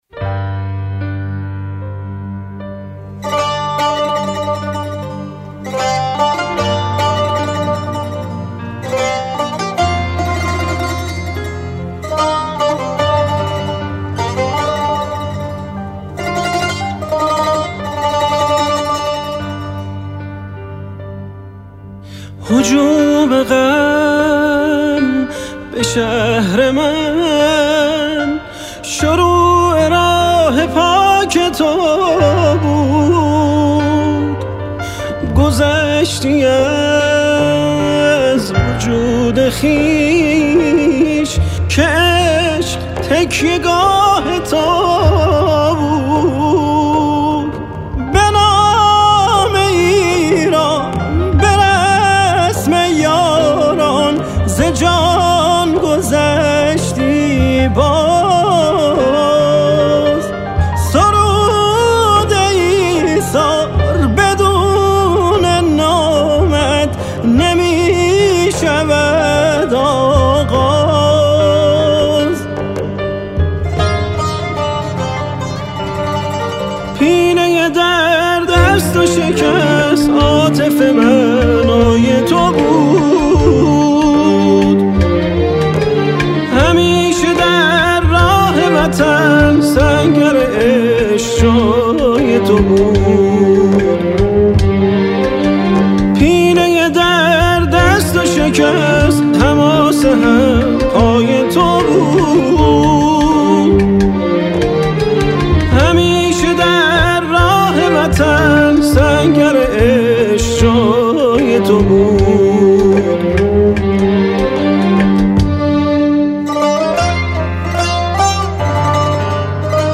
به عنوان تنها دوقلوهای آواز ایران
(موسیقی و تنظیم)
آواز